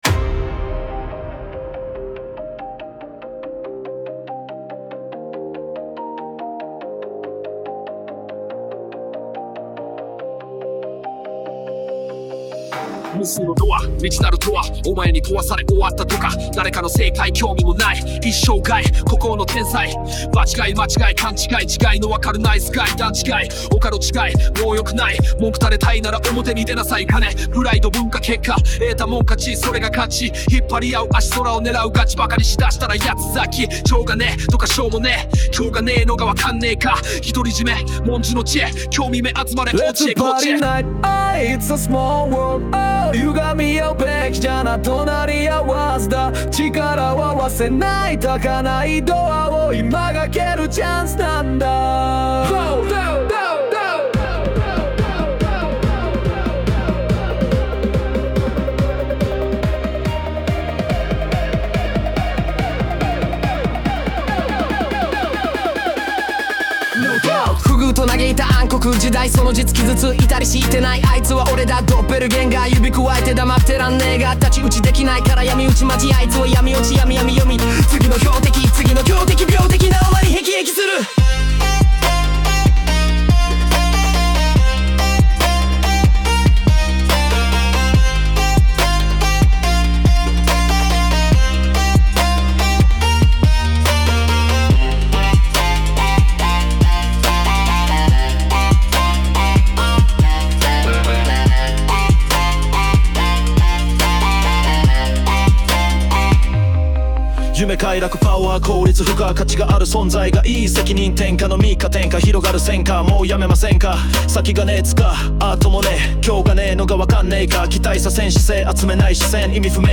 BPM142
ジャンルは　Rap × EDM
nextdoor_ai.mp3